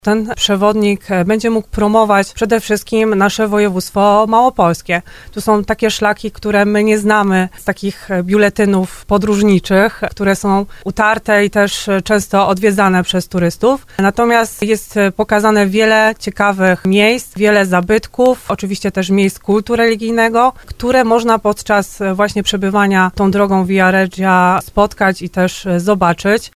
Marta Malec-Lech z zarządu województwa, która była gościem programu Słowo za Słowo powiedziała, że przewodnik opisuje miejsca i zabytki, o których mało wiemy i których nie znajdziemy w innych tego typu publikacjach.